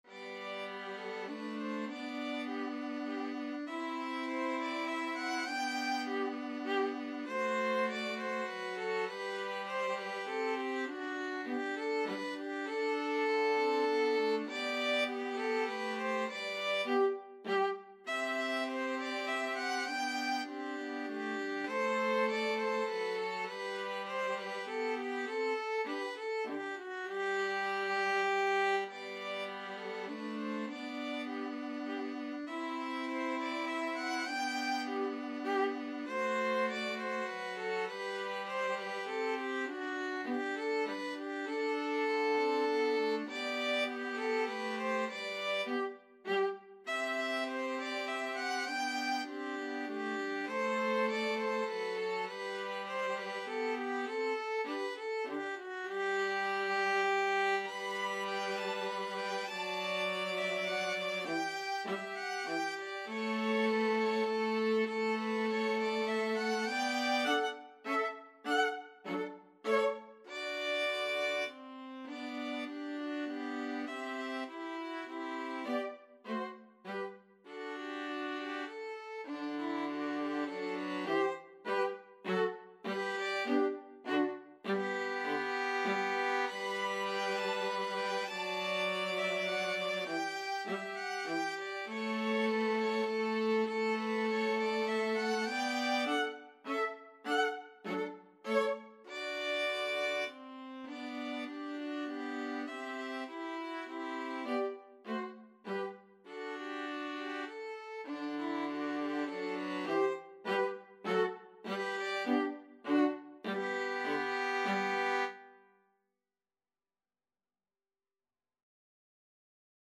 Violin 1Violin 2Viola
Classical (View more Classical 2-violins-viola Music)